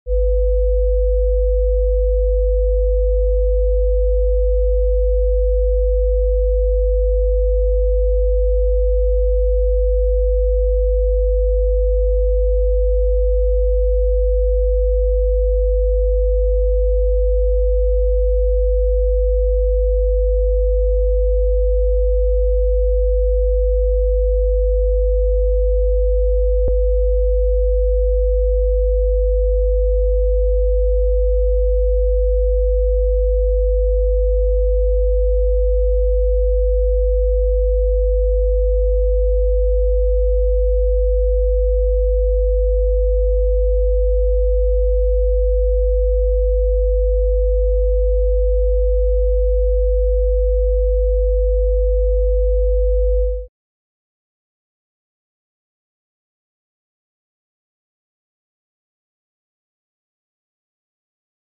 Both include two sine waves, one at 50 Hz and the other at 500 Hz, played at the same volume. On one track, the two tones are played together.
simul_tones.mp3